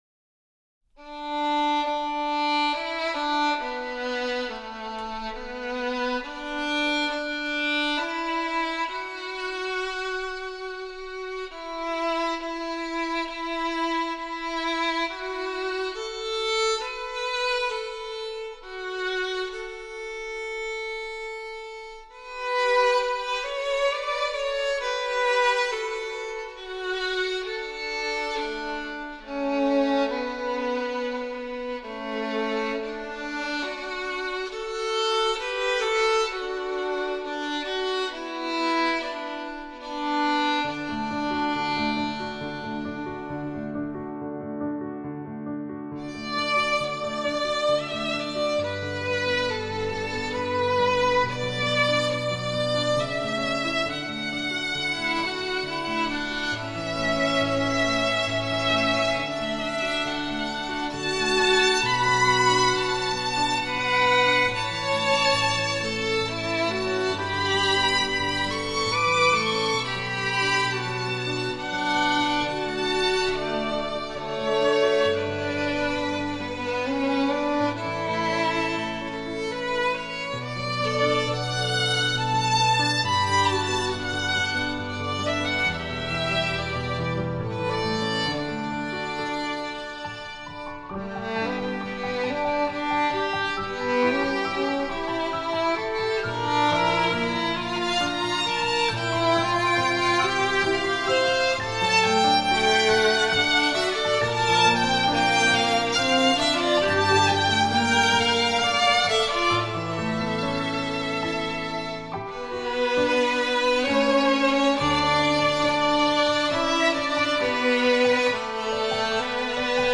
Instrumentation: 2 Violins and Piano